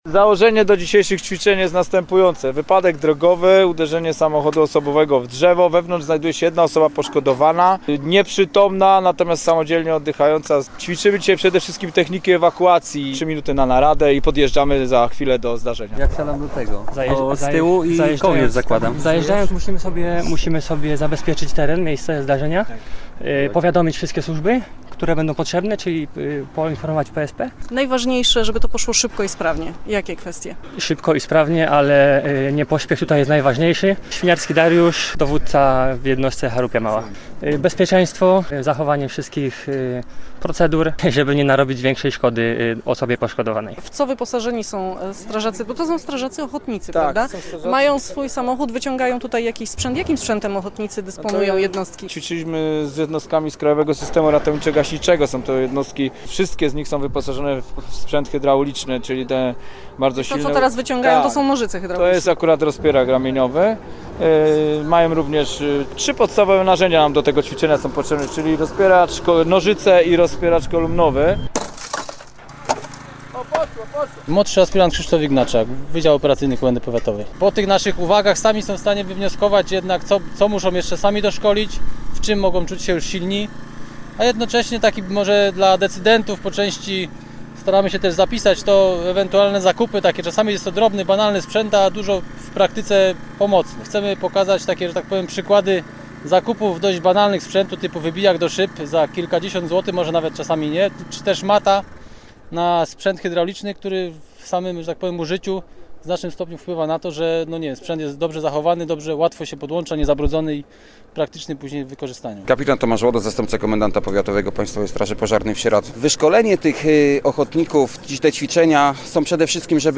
Nazwa Plik Autor Manewry strażackie audio (m4a) audio (oga) Ćwiczenia strażaków ochotników przeprowadzane są raz w roku, ponieważ trudno częściej zwołać ponad 20 jednostek zrzeszonych w krajowym systemie.